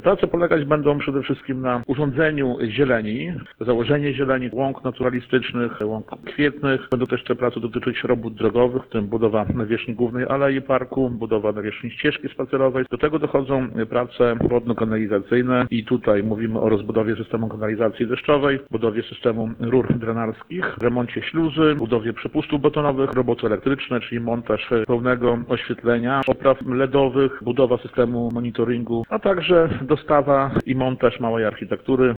– Problem wzrostu cen wykonawców został rozwiązany. Radni zdecydowali, by zwiększyć nakłady finansowe na ten cel i czwarty przetarg zakończy się podpisaniem umowy z wykonawcą prac w Parku Solidarności – zapewnia Dariusz Latarowski, burmistrz miasta.